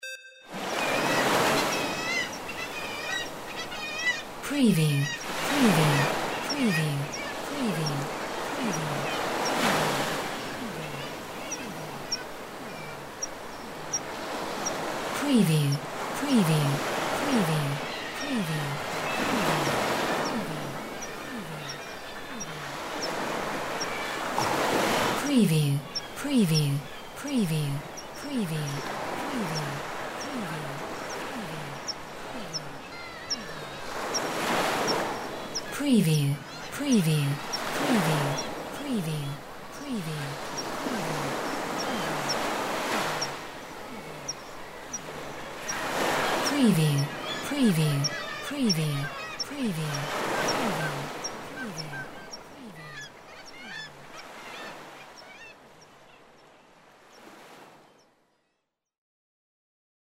Birds Kittiwake Sea Cliff Ambience b
Increased cave like ambience
Stereo sound effect - Wav.16 bit/44.1 KHz and Mp3 128 Kbps
previewAMB_BIRDS_KITTIWAKE_SEACLIFF_WBHD1B.mp3